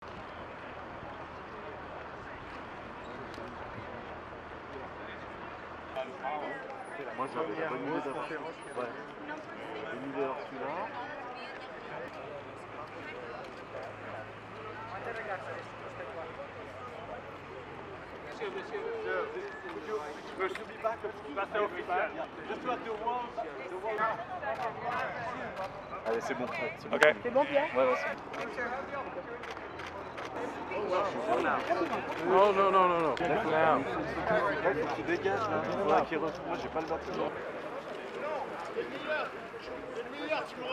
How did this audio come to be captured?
Speakers of Parliament gather for a group photo at the entry of the General Assembly Hall, United Nations, Geneva, Switzerland - 30 Jul 2025